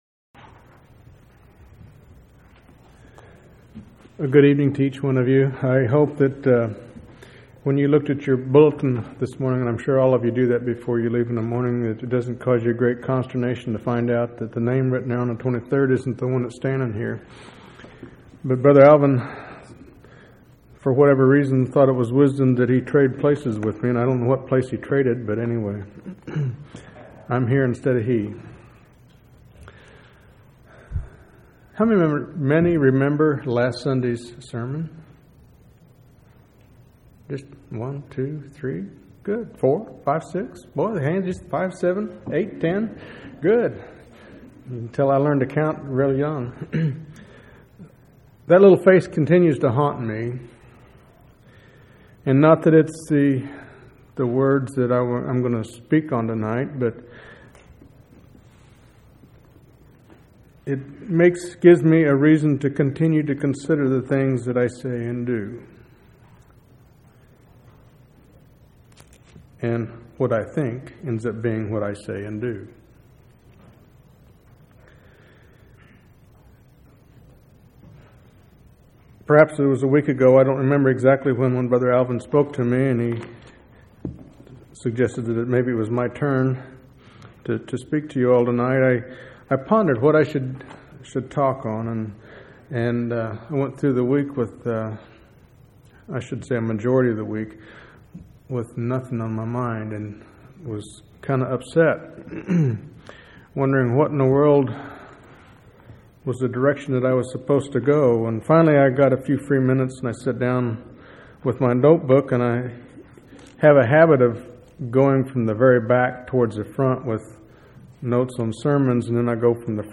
1/23/2005 Location: Temple Lot Local Event